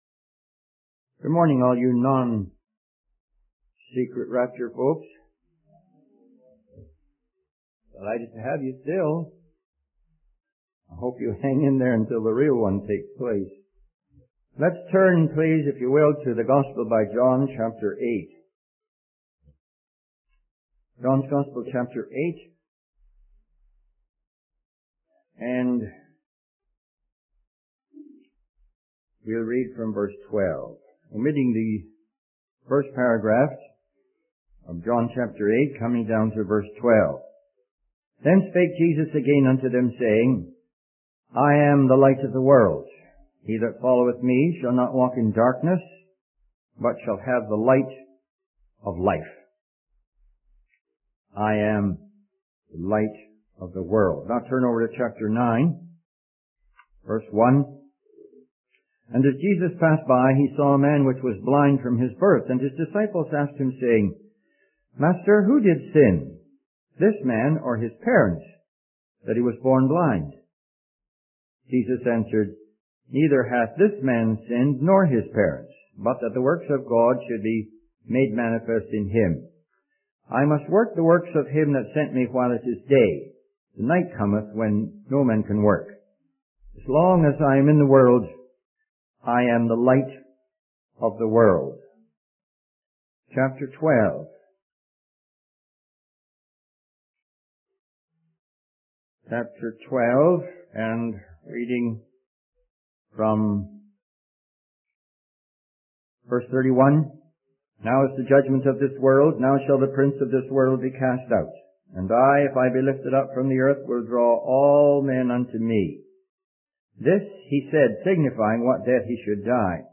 In this sermon, the speaker discusses the issue of distinguishing between a fire and a tanner of 50 in a field where everything looks the same. He then transitions to talking about how people handle money and how they trust others to identify the value of different bills.